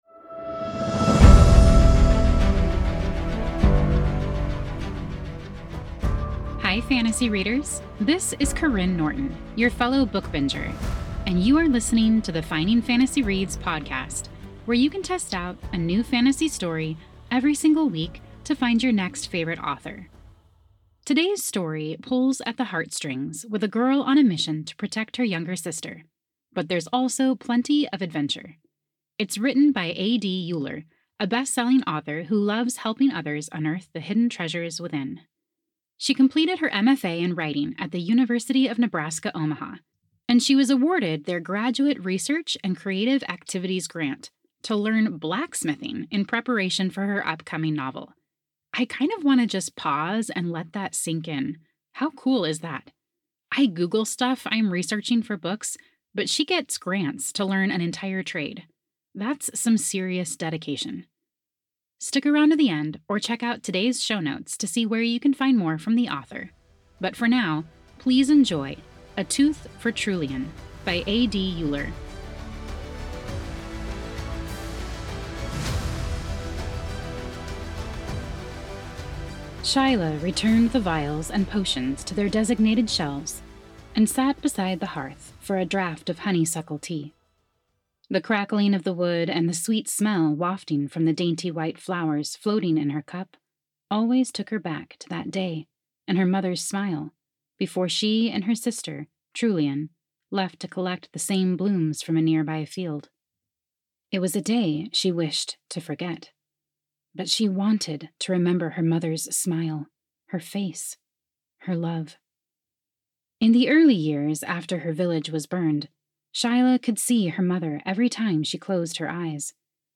YA Fantasy Short Story